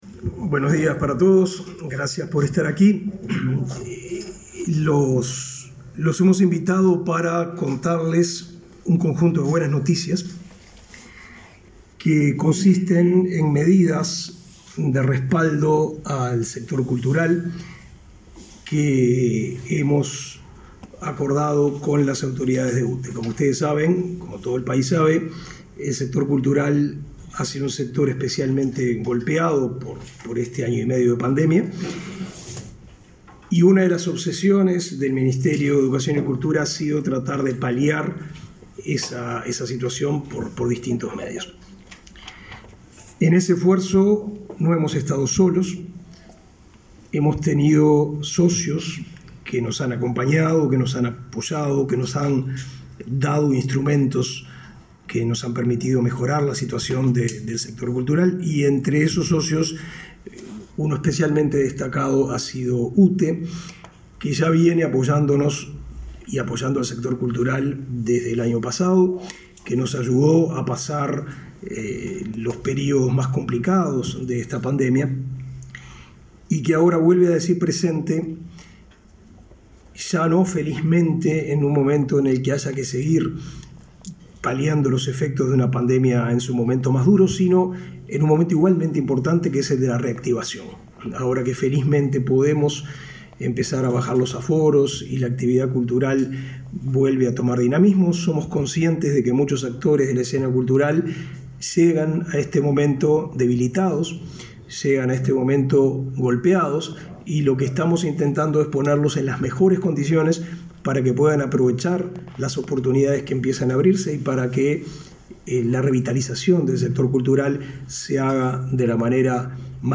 Conferencia de prensa de autoridades de UTE y MEC por medidas que benefician a instituciones culturales y salones de fiestas
El ministro de Educación y Cultura, Pablo da Silveira; la directora nacional de Cultura, Mariana Wainstein, y la presidenta de UTE, Silvia Emaldi, anunciaron, este 10 de setiembre, la implementación del beneficio de exoneración, durante el segundo semestre de 2021, de los cargos fijos y por potencia contratada del servicio de energía eléctrica a las instituciones culturales y salones de fiestas y eventos.